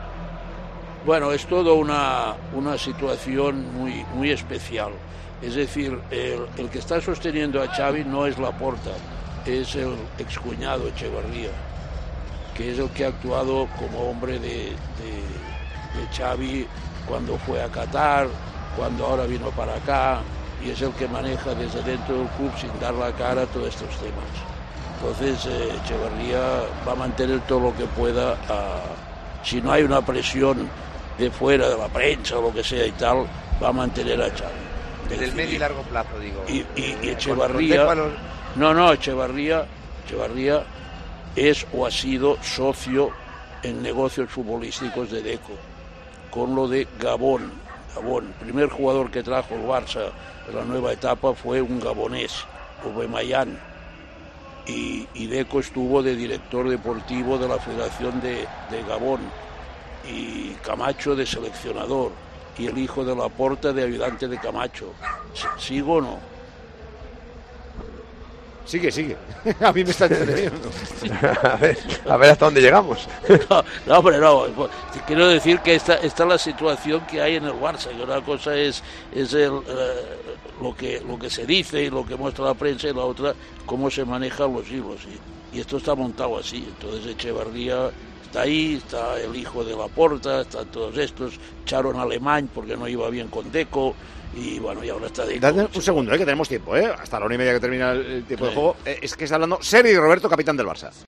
El comentarista del Barcelona en Tiempo de Juego habló del futuro de Xavi en en banquillo después de la dura derrota ante el Real Madrid en la final de la Supercopa.